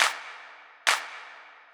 K-4 Clap.wav